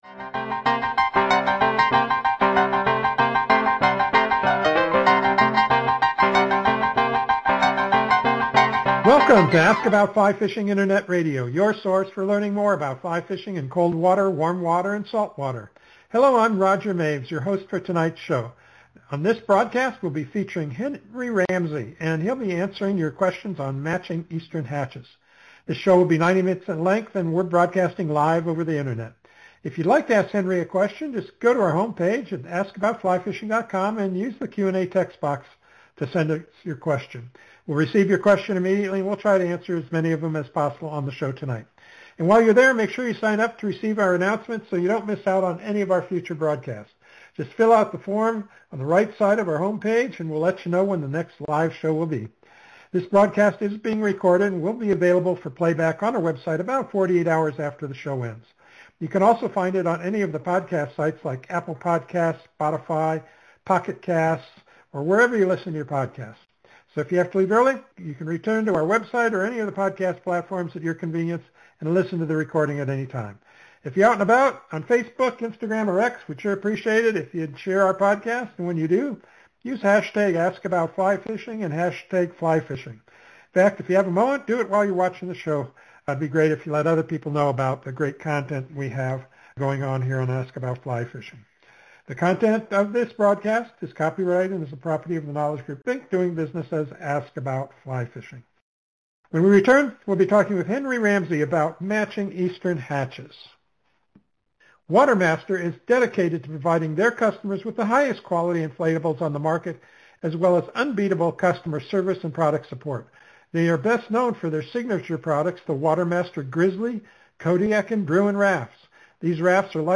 Expert fly fishers tell you in their own words the secrets to their success to fly fishing in both freshwater and saltwater.
Just a few of the questions asked and answered during the interview: